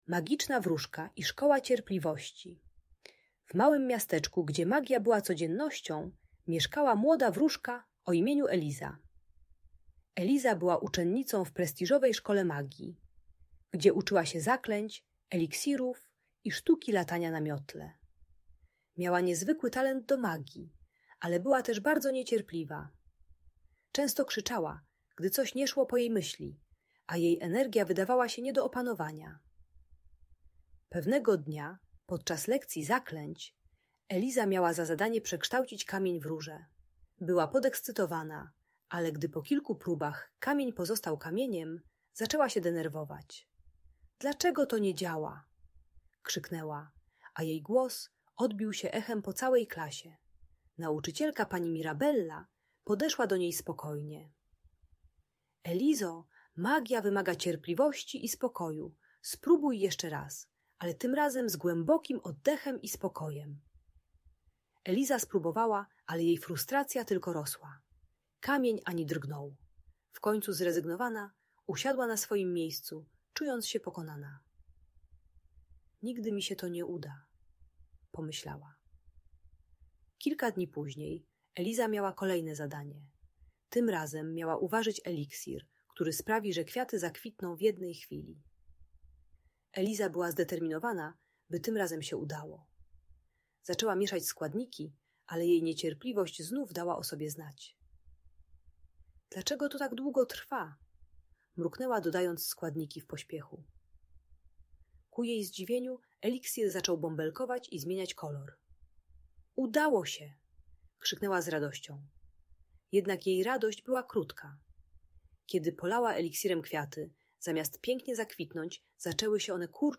Magiczna Wróżka: - Niepokojące zachowania | Audiobajka